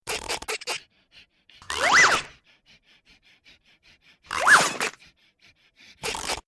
rollover.ogg